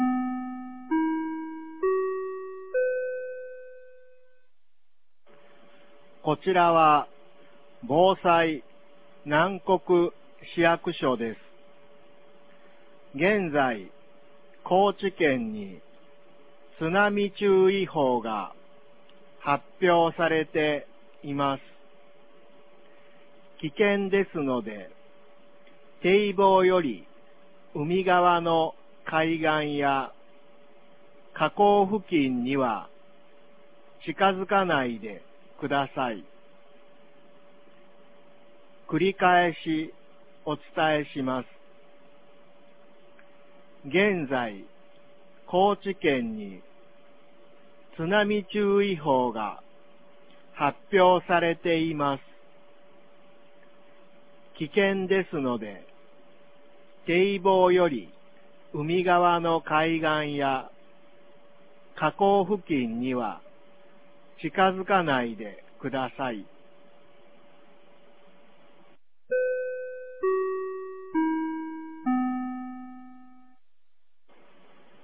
2022年01月16日 09時36分に、南国市より放送がありました。